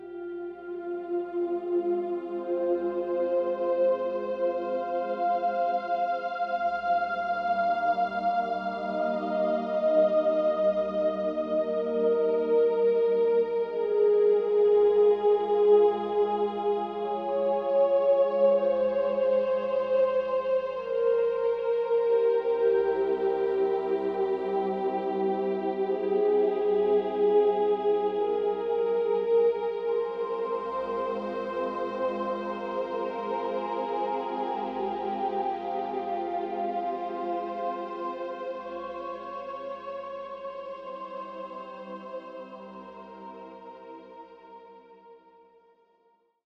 Very ambient and soothing.